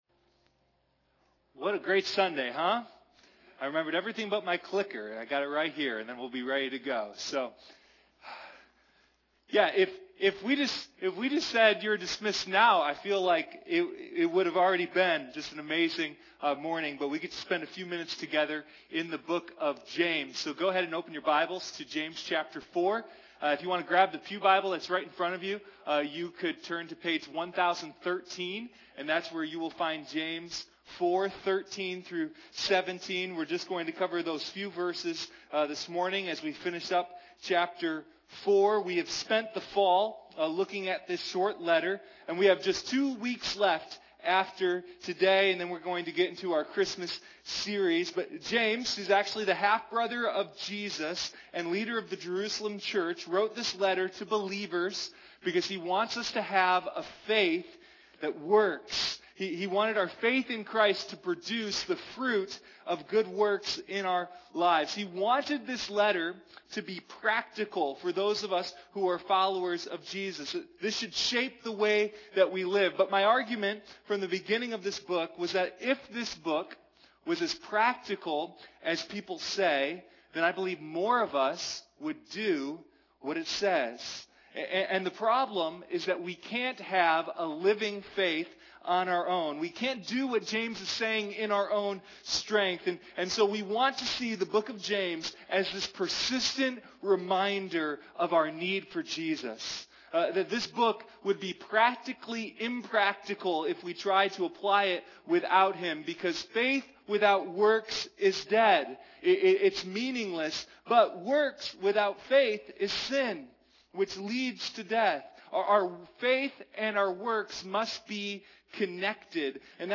Sunday Morning Living Faith: The Book of James